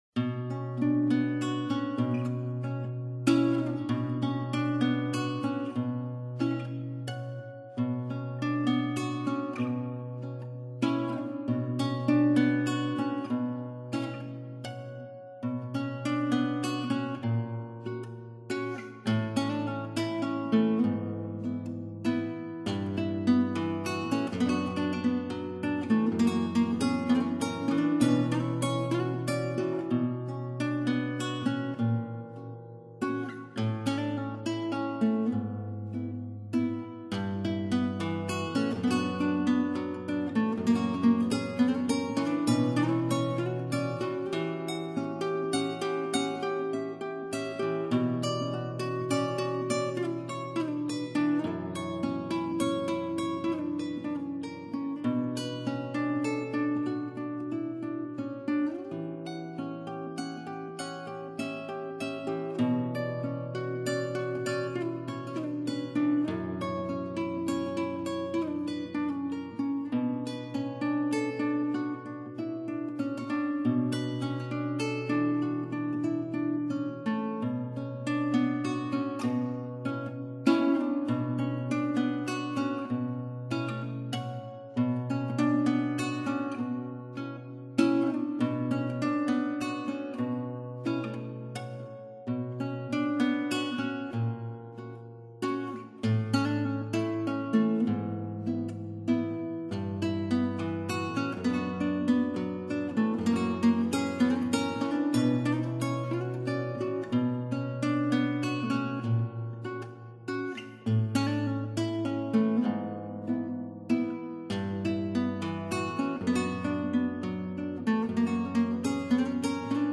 But it seemed to me more like a guitar solo piece.
classical guitar